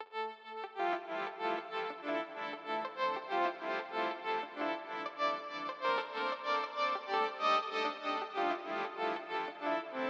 Tag: 95 bpm Trap Loops Synth Loops 1.70 MB wav Key : Unknown